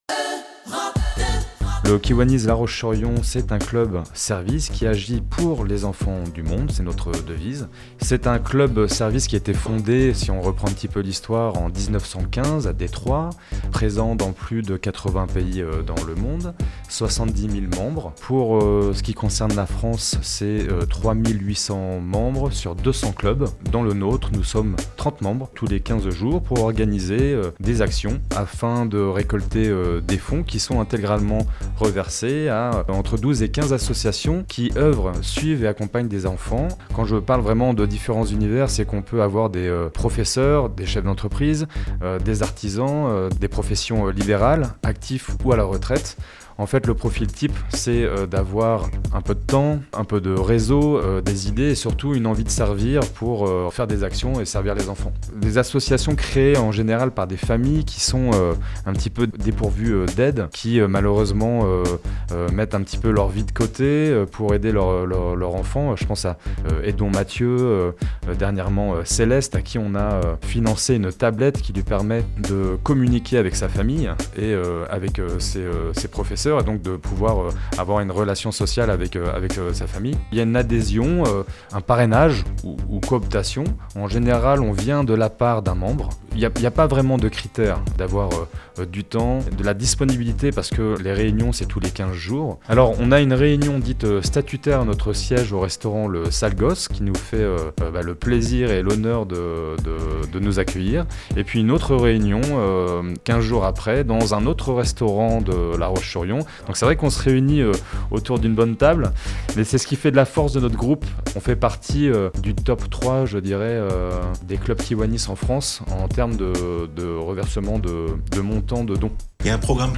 EUROPE 2 VENDÉE